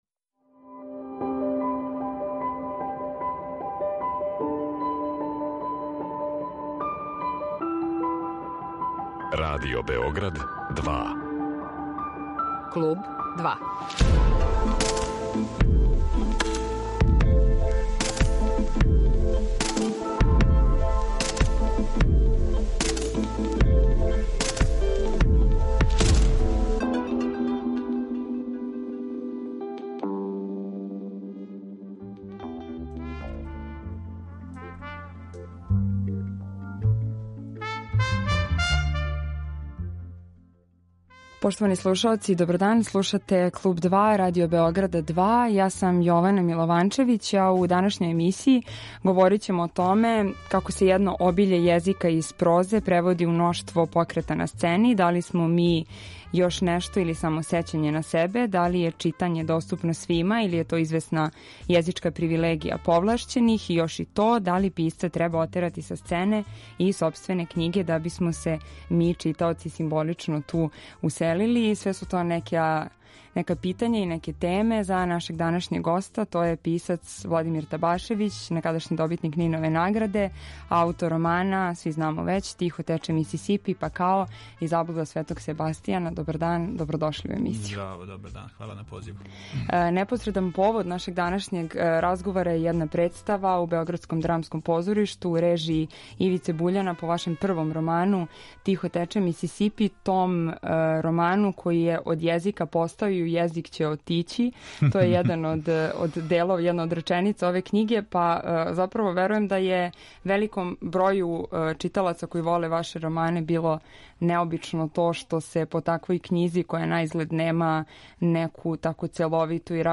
Гост је писац Владимир Табашевић